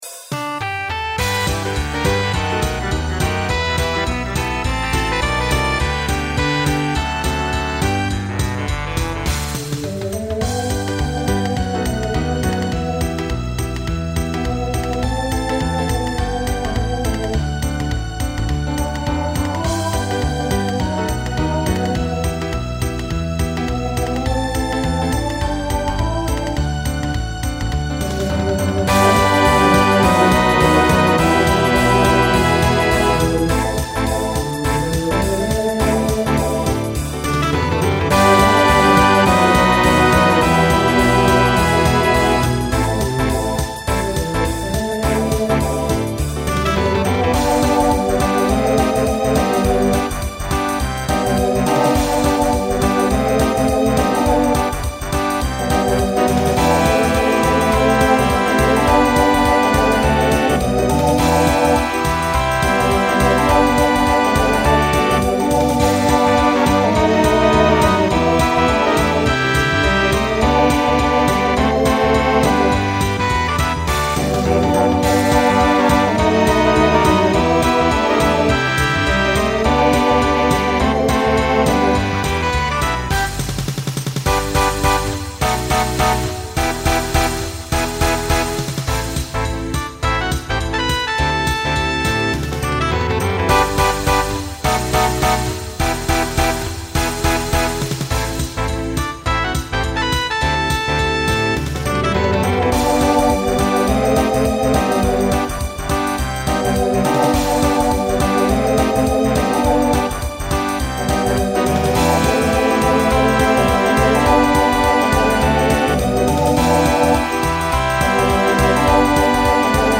Voicing TTB
Genre Pop/Dance , Swing/Jazz